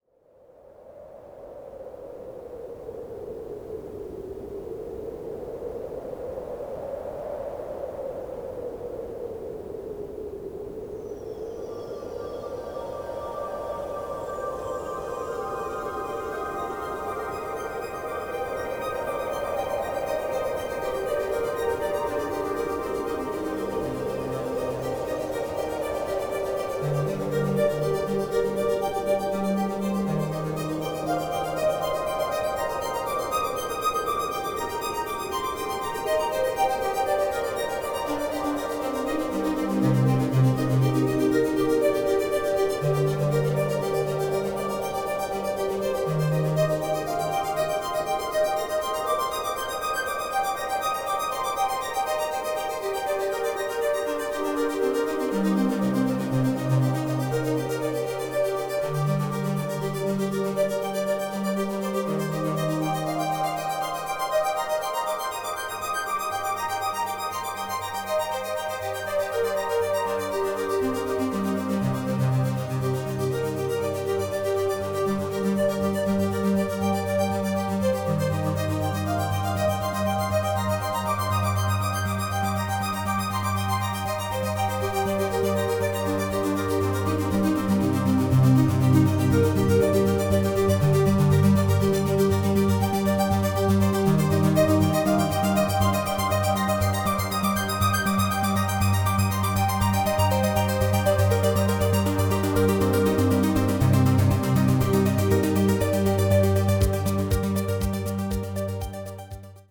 harmonic
spacey
dreamy
sequencer-based
relaxing
Music to dream to.